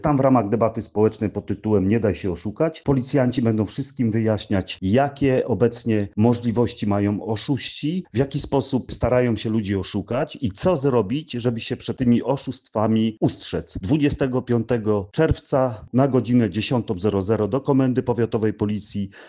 O inicjatywie opowie radny powiatowy i pomysłodawca spotkania – Mariusz Malec: